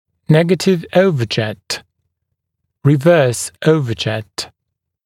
[‘negətɪv ‘əuvəʤet] [rɪ’vɜːs ‘əuvəʤet][‘нэгэтив ‘оувэджэт] [ри’вё:с ‘оувэджэт]отрицательное горизонтальное перекрытие, обратное сагиттальное перекрытие